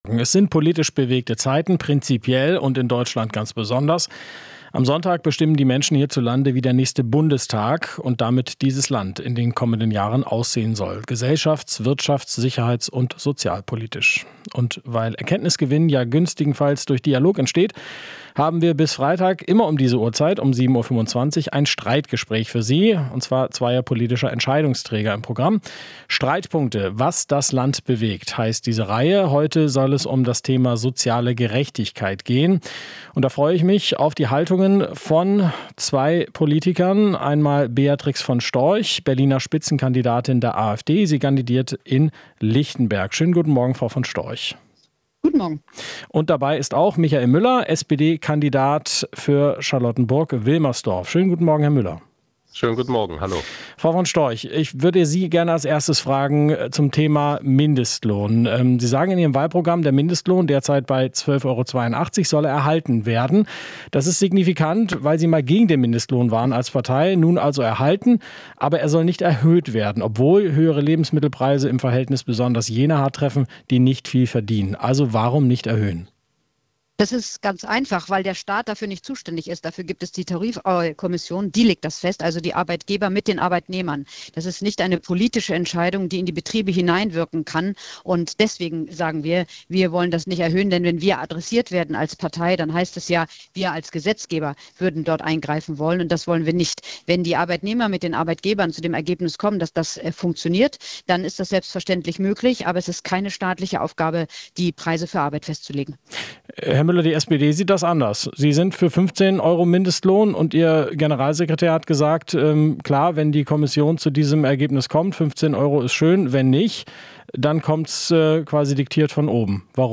Ein Streitpunkt ist die soziale Gerechtigkeit. Über ihre Konzepte für den Umgang mit Arm und Reich streiten bei uns Beatrix von Storch (AfD) und Michael Müller (SPD).
Interview - Streitpunkt Soziale Gerechtigkeit: Wie umgehen mit Arm und Reich?